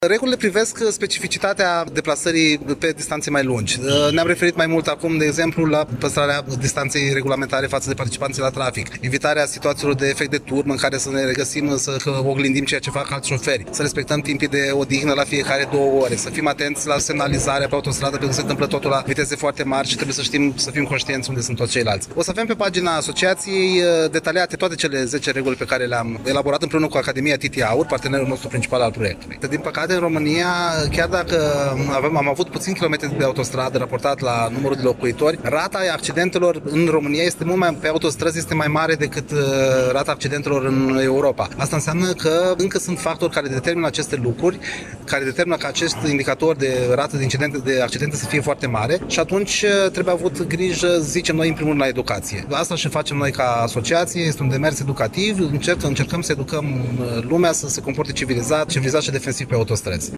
Conferința organizată pe această temă, a educației pentru siguranță în trafic, a avut loc în Sala Mare a Primăriei Iași.